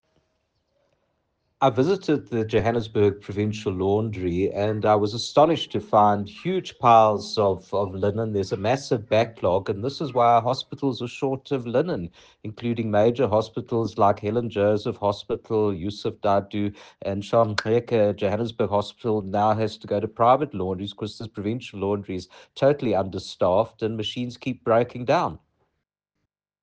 soundbite by Dr Jack Bloom MPL.